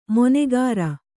♪ monegāra